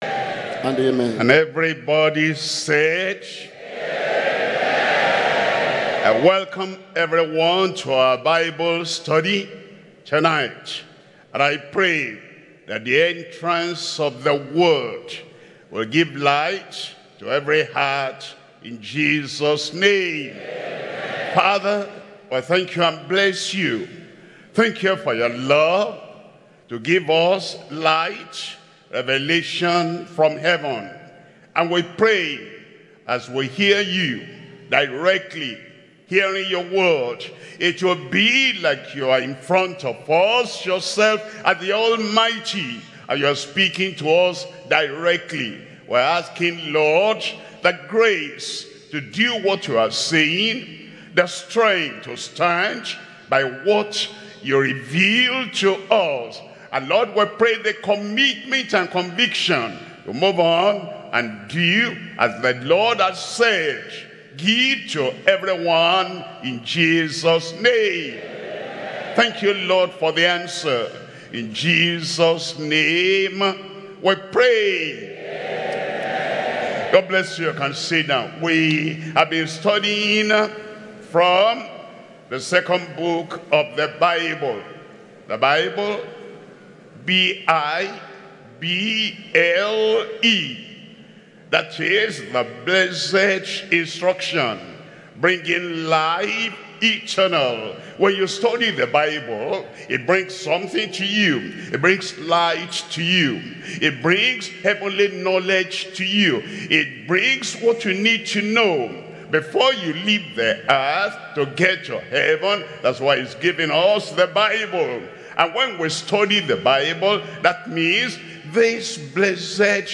Sermons – Deeper Christian Life Ministry, United Kingdom
Bible Study